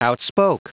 Prononciation du mot outspoke en anglais (fichier audio)
Prononciation du mot : outspoke